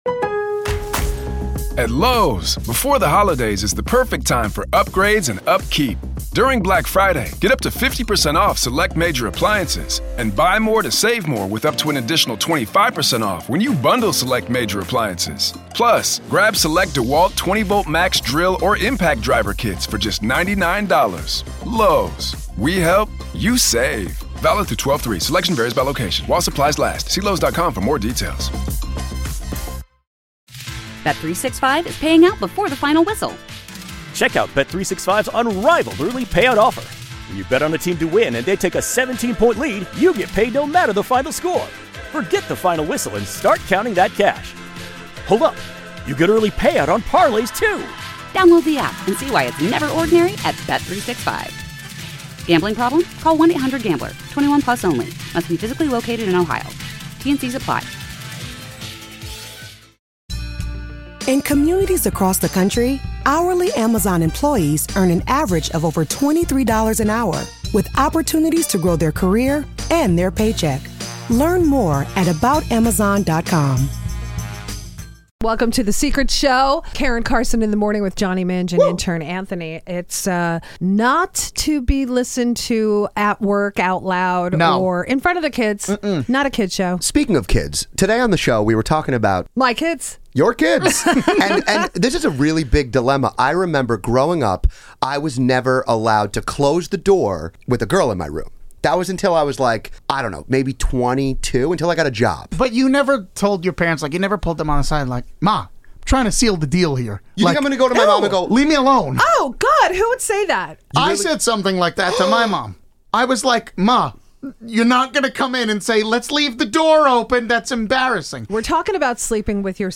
Your favorite morning show like you've never heard them before raw, uncensored and unedited!